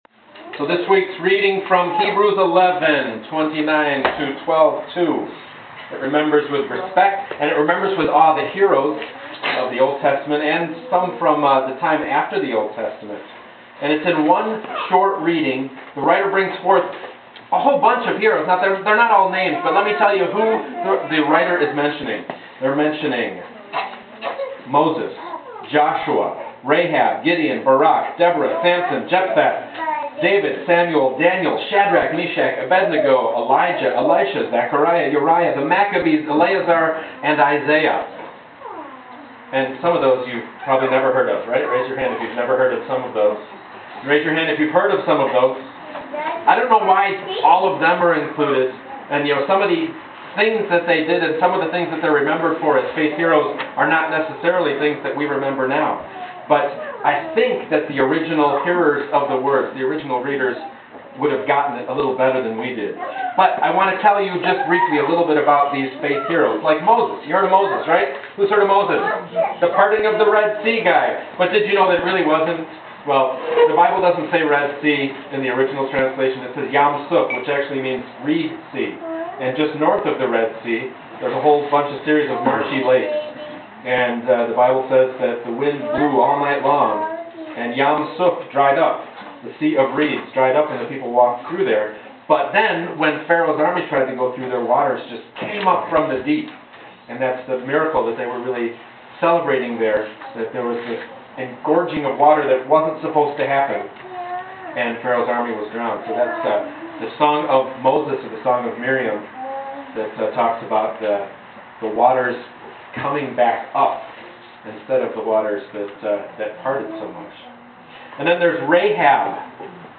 This week's i.ucc "Opening the Bible" blog entry (Faith Heroes) has been expanded and deepened (does that make any sense?) and given a title that has very little to do with the sermon for tomorrow (today?) at Union Congregational Church in Somonauk .